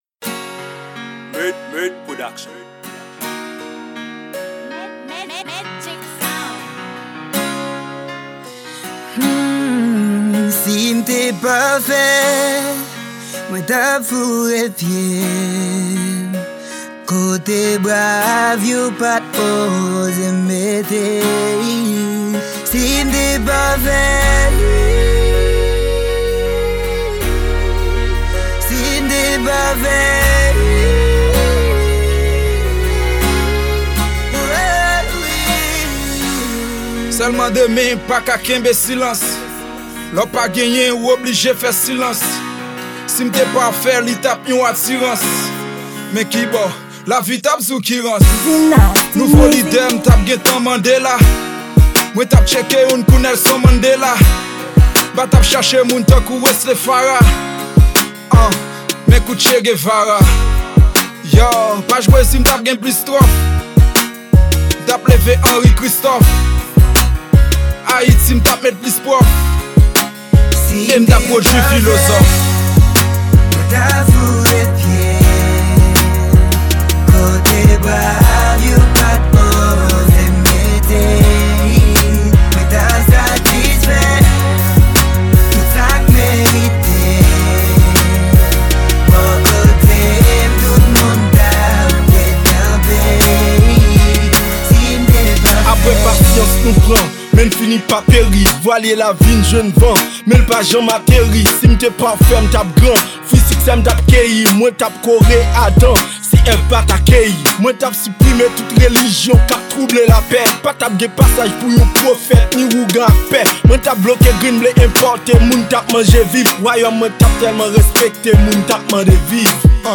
Genre: Rnb-Rap.